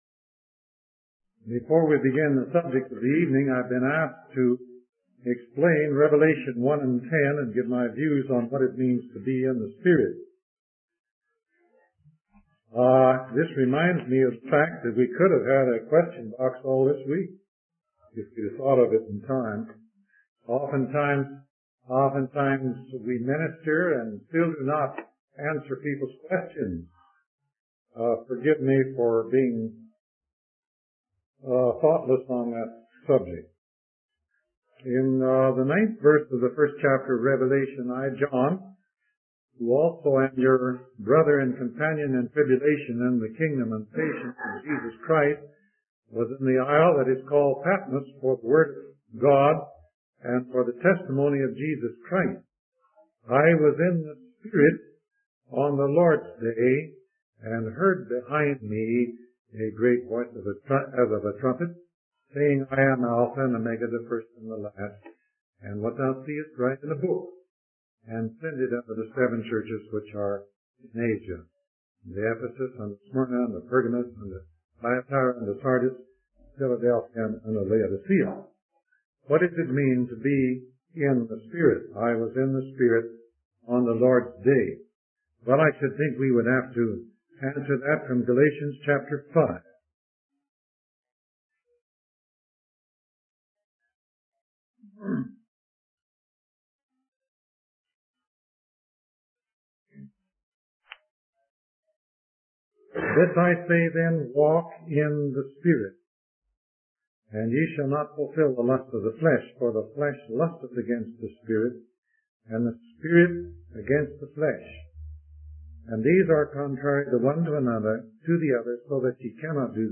In this sermon, the speaker begins by discussing Revelation 1 and 10 and shares their interpretation of what it means to be 'in the Spirit.' They then move on to Romans 8:10, explaining that God will quicken our mortal bodies when Jesus Christ returns, and this transformation will be done by the power of the Holy Spirit.